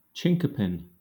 Ääntäminen
Southern England
IPA : /ˈt͡ʃɪnkəpɪn/